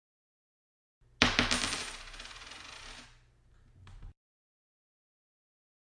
钱的声音 " 硬币弹起。掉落在木头桌子上（中）。
Tag: 退回 落下 硬币